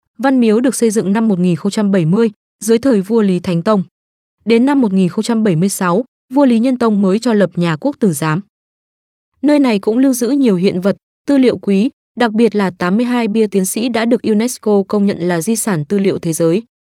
特点：轻快活力 大气浑厚 稳重磁性 激情力度 成熟厚重
越南语男女样音